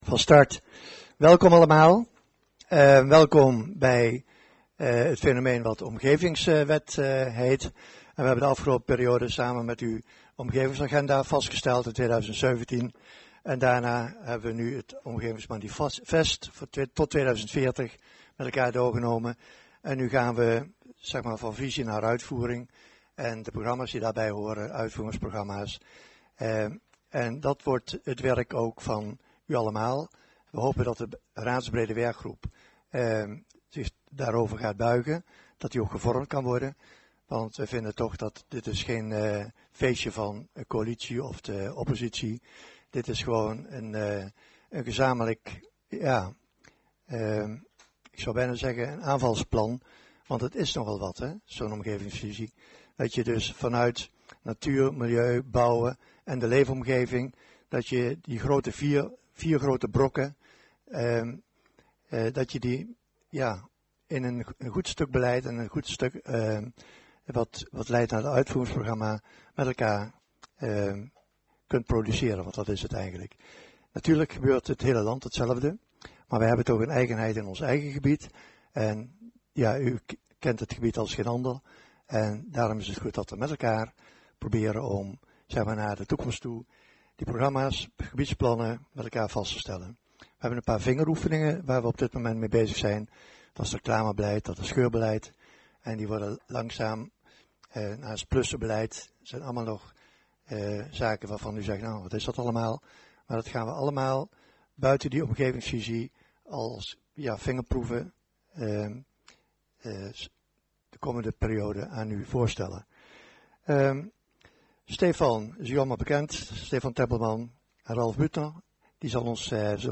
Locatie gemeentehuis Elst Toelichting Informatiebijeenkomst van College over Omgevingsvisie Overbetuwe Agenda documenten 18-09-04 Opname 2.